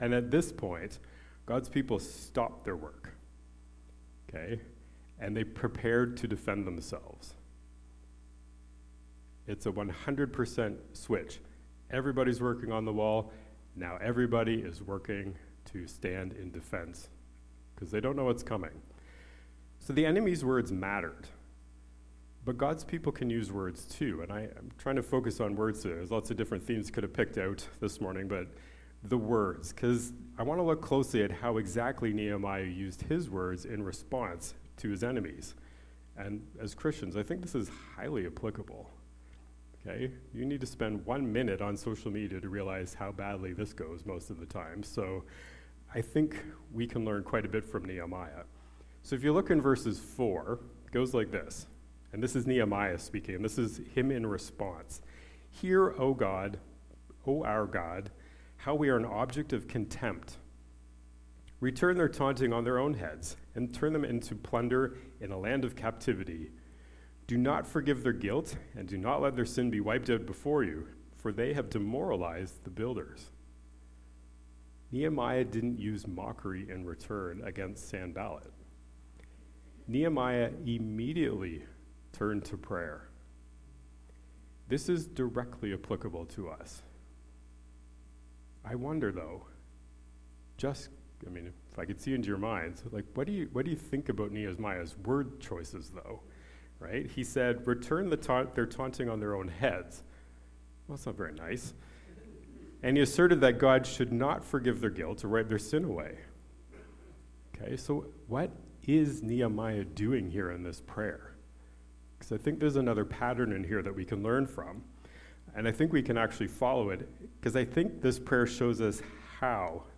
Due to technical difficulties, the recording is missing the first part of the message.